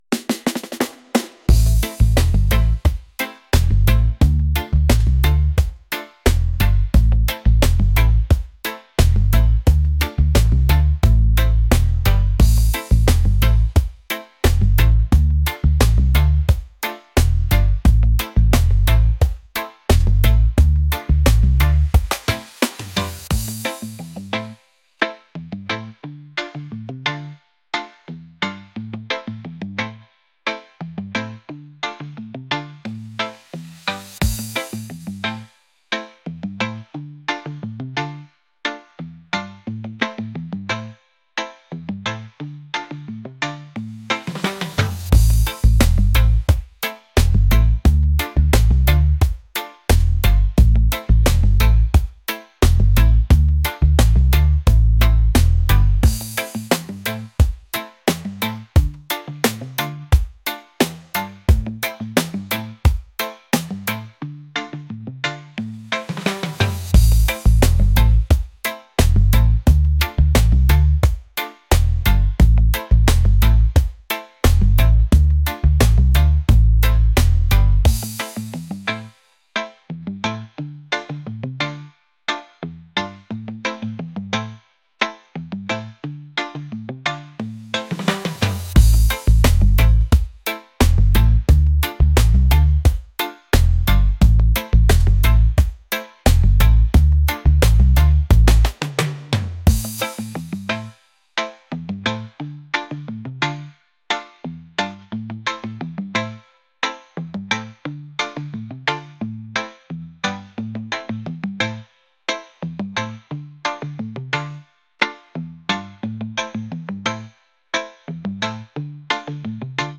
reggae | lofi & chill beats | acoustic